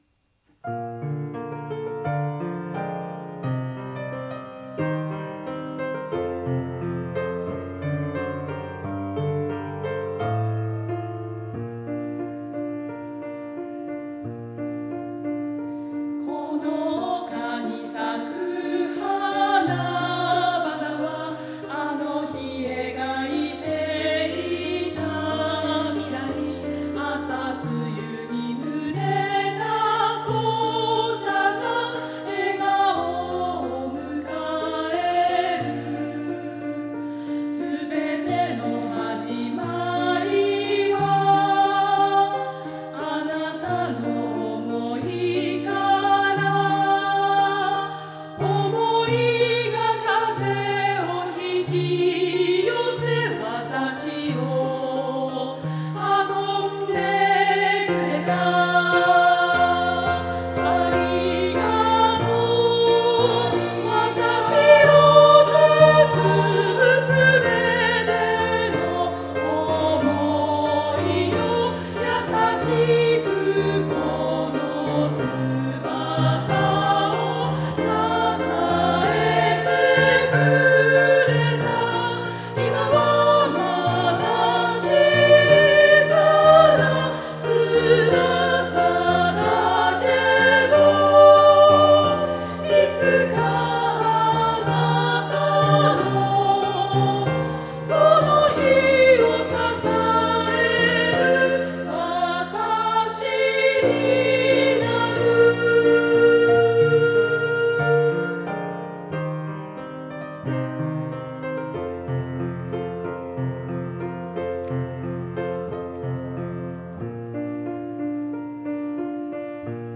職員による歌唱